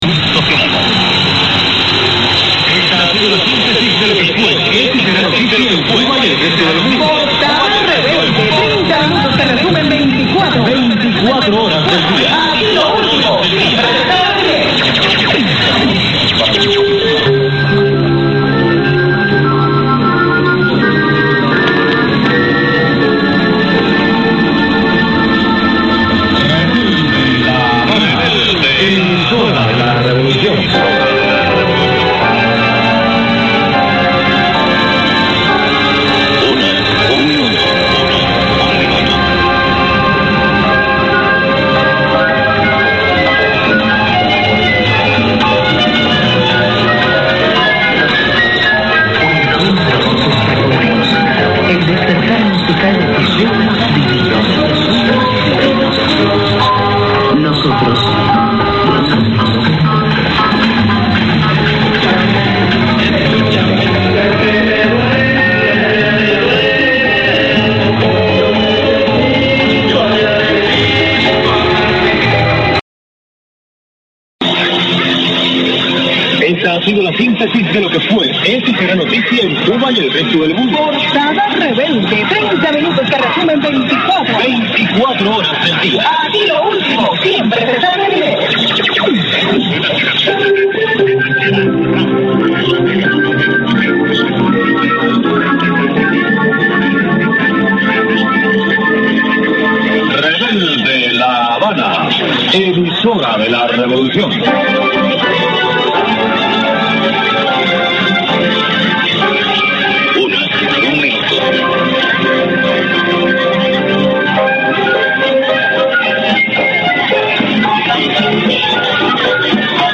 X Band has been so choc a block that many stations are heard with strong signals at the same time.
110322_0600_1520_ss_lv_unid_poss_columbia.mp3